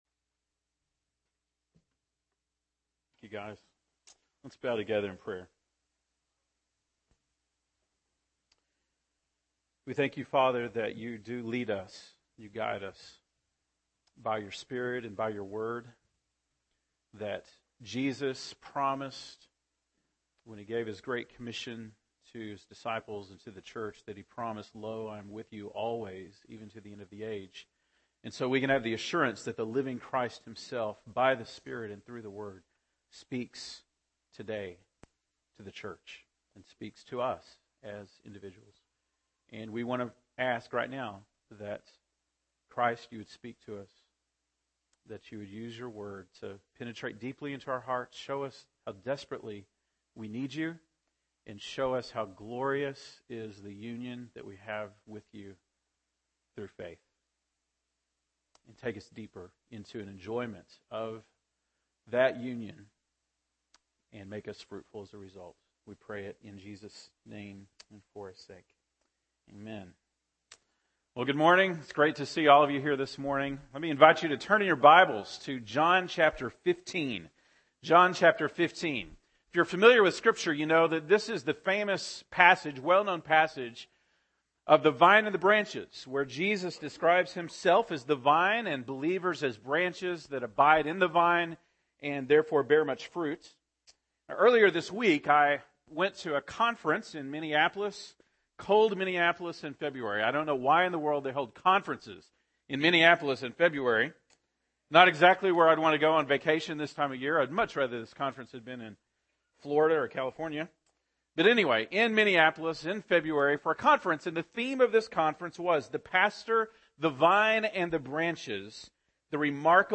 February 9, 2014 (Sunday Morning)